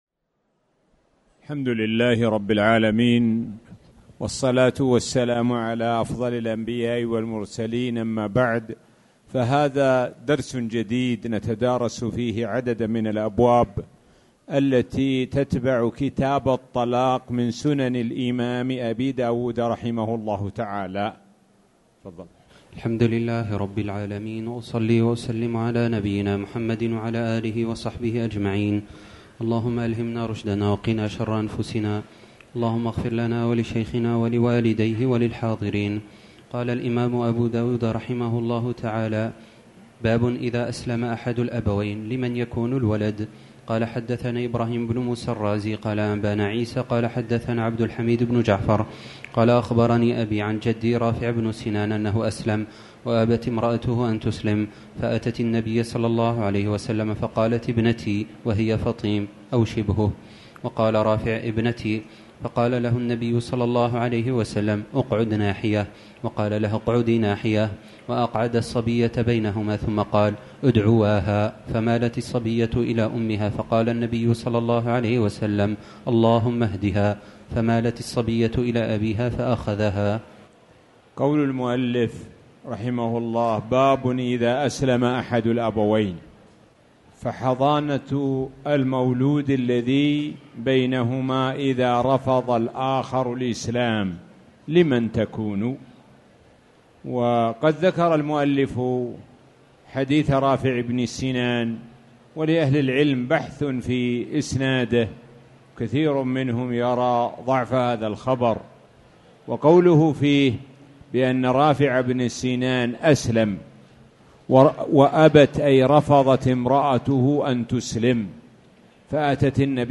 تاريخ النشر ١٢ ذو القعدة ١٤٣٨ هـ المكان: المسجد الحرام الشيخ: معالي الشيخ د. سعد بن ناصر الشثري معالي الشيخ د. سعد بن ناصر الشثري باب الظهار